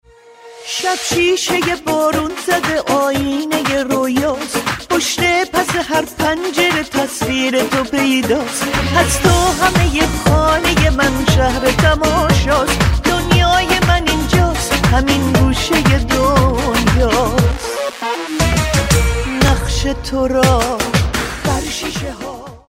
زنگ موبایل شاد